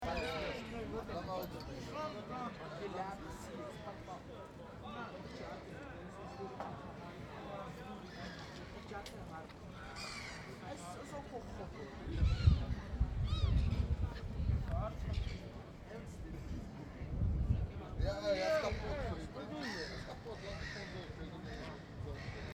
08/03/2015 16:00 Autour de la gare centrale d'Amsterdam, les tramways jouent de la cloche et les vélos filent droit.
C’est le printemps à Amsterdam.